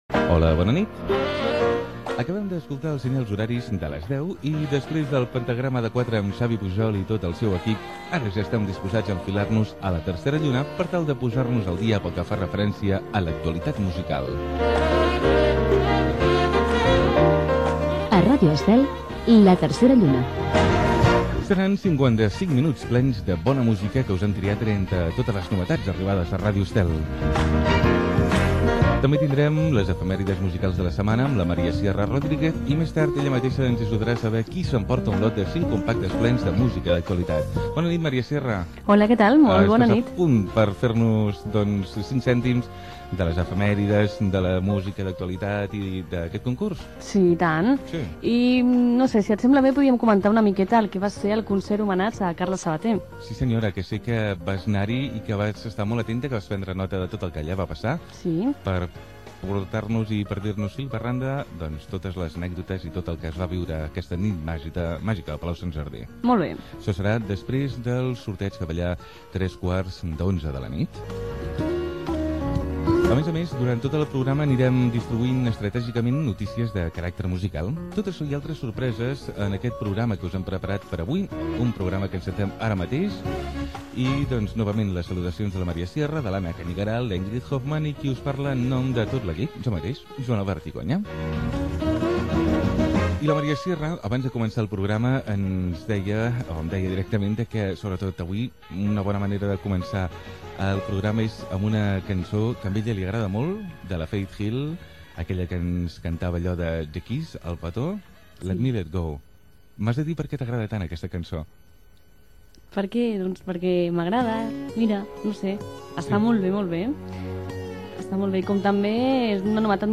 Presentació, indicatiu del programa, sumari, concert, equip, tema musical
Musical
FM